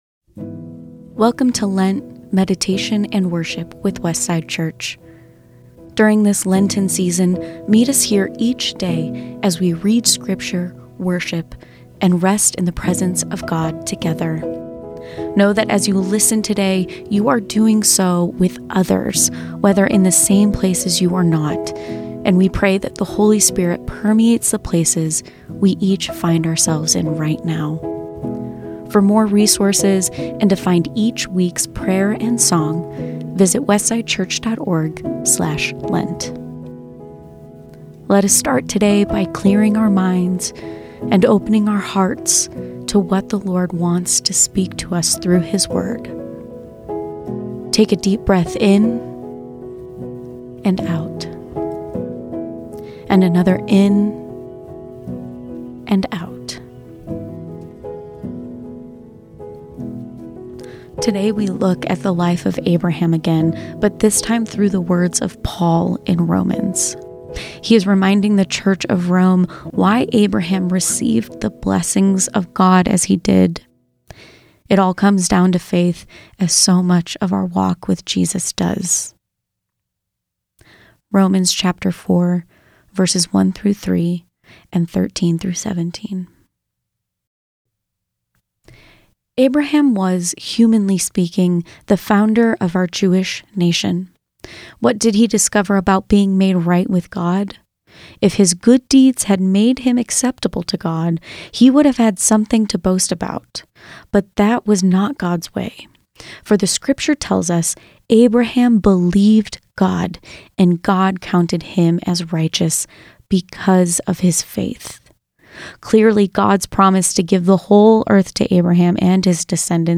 A reading from Romans 4